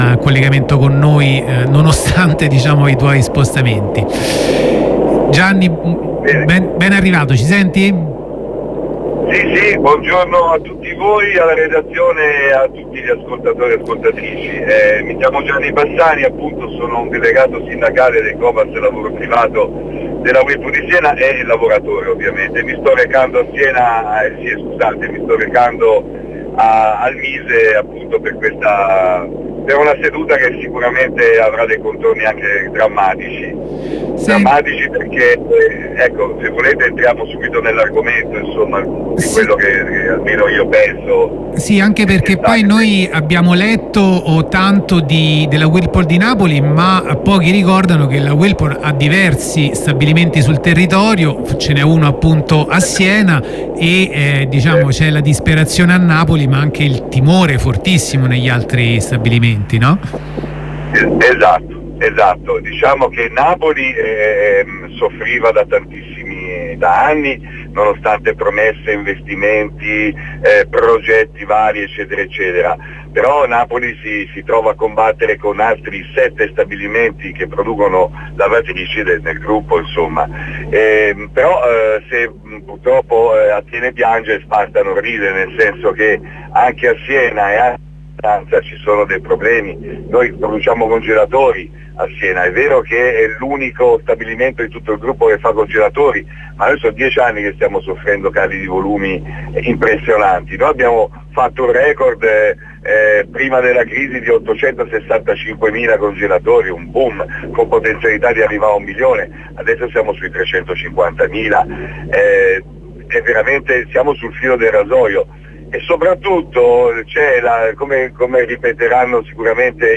L'intervento di un lavoratore di Siena
Ai nostri microfoni, un lavoratore di Siena e un altro di Napoli.